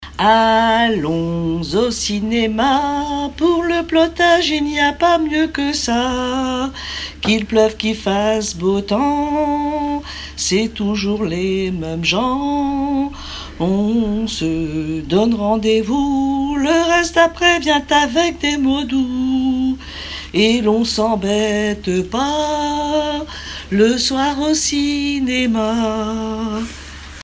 Genre strophique
formulettes enfantines, chansons en français et en breton
Pièce musicale inédite